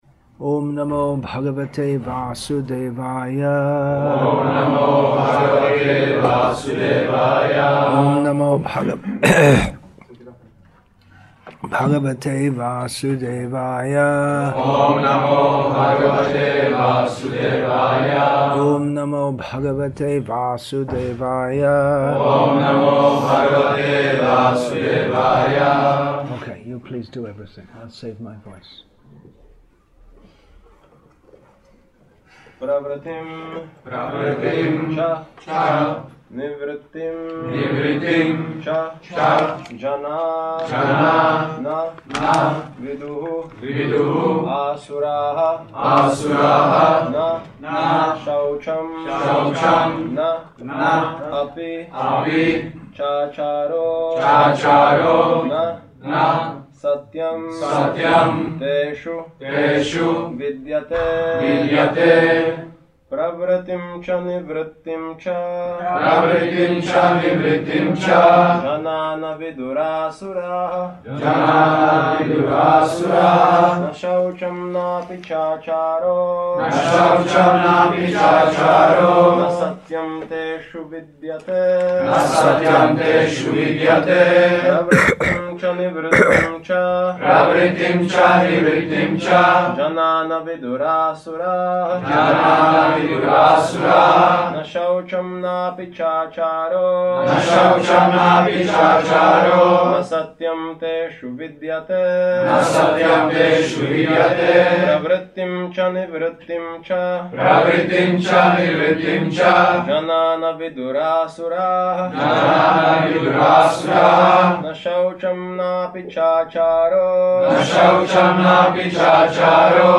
English with Český (Czech) Translation; Brno, Czech Republic , Czech Republic Bhagavad-gītā 16.7 Play Download Add To Playlist